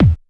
Kick_16.wav